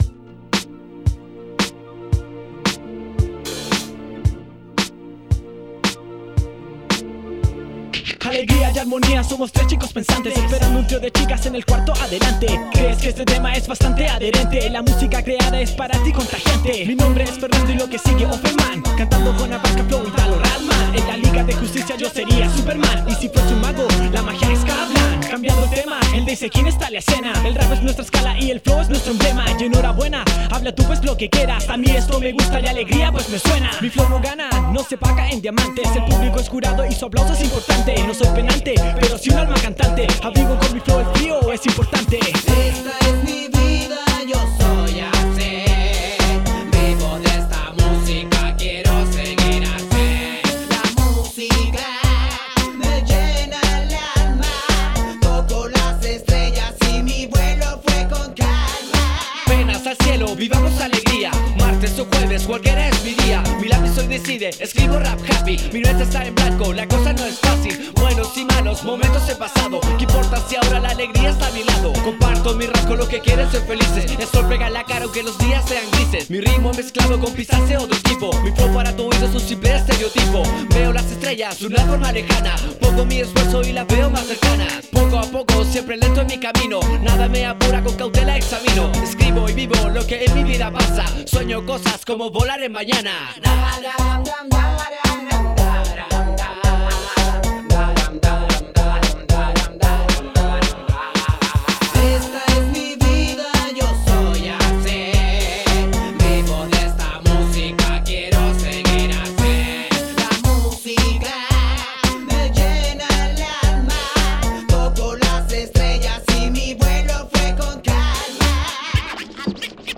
Rap echo desde puente alto / la florida:
Escraches sacados desde un disco de Peter pan, la caperucita roja y un curso d inglés